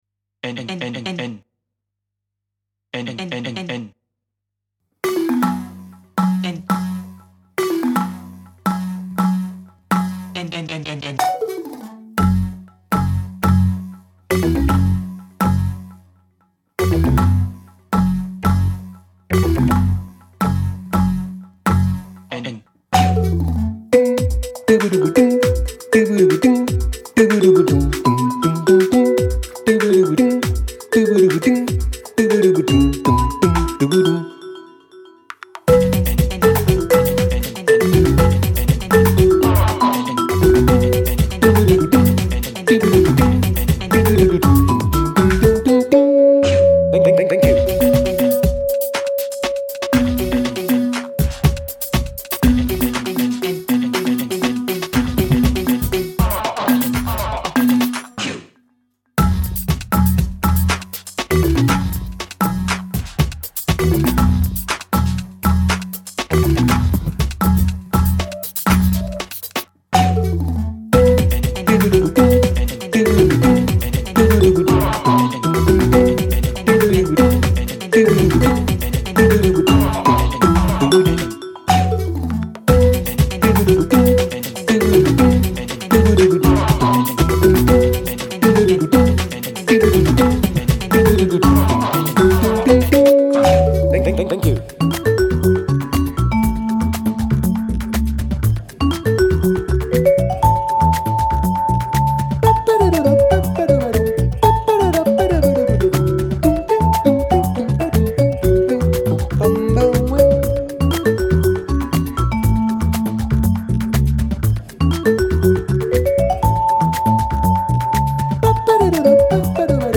melodic percussion
balafon, steeldrum, vocals, derbouka
vibraphone, e-vibes